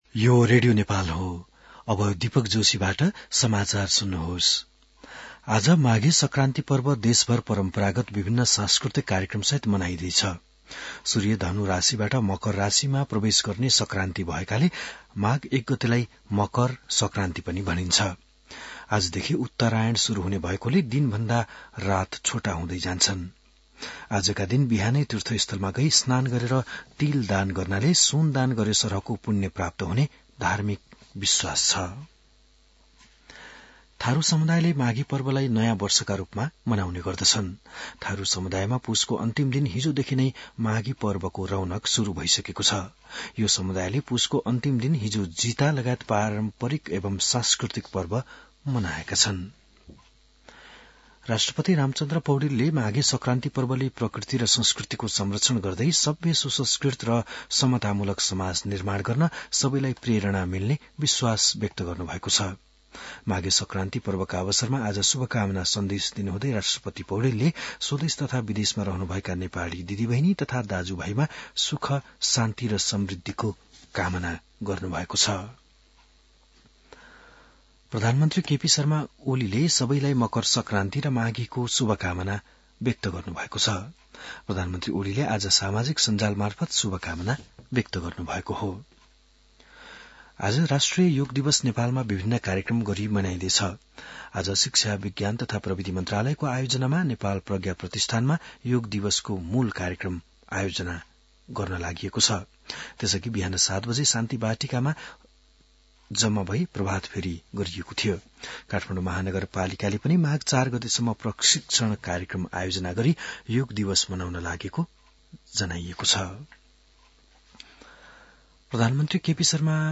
An online outlet of Nepal's national radio broadcaster
बिहान १० बजेको नेपाली समाचार : २ माघ , २०८१